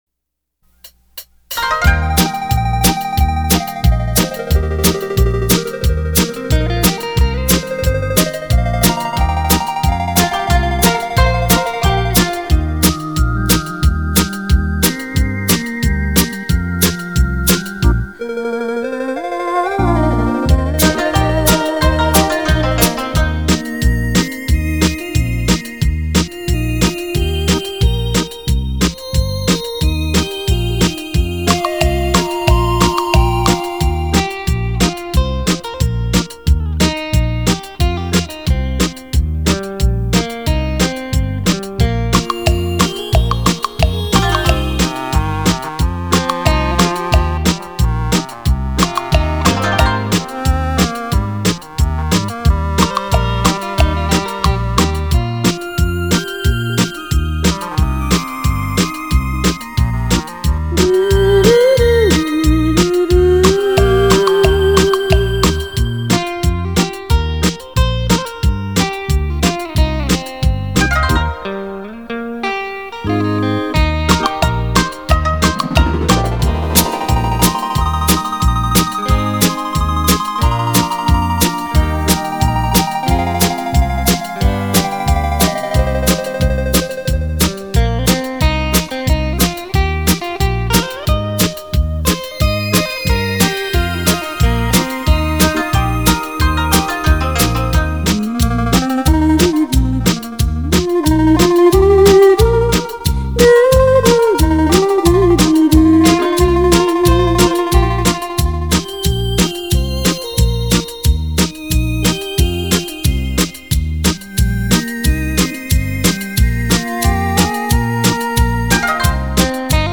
丝丝萦绕的音韵 旋律中流淌的音符 是否触动了你的心